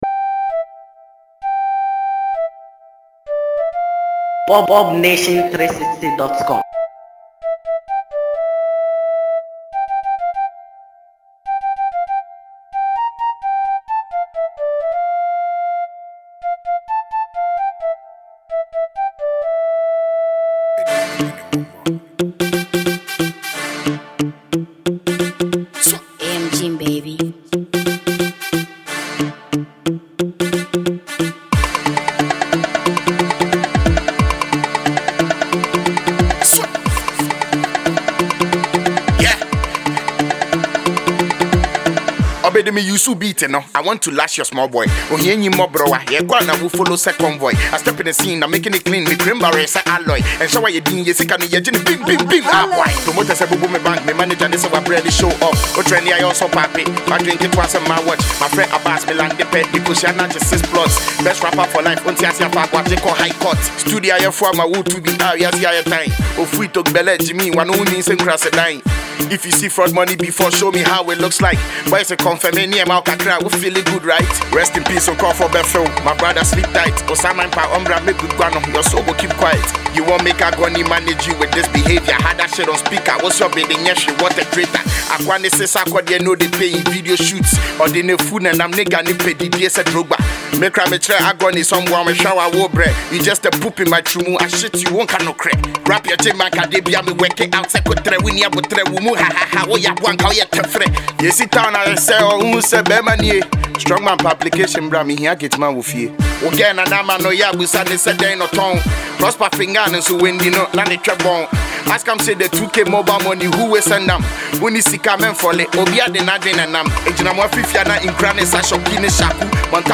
diss song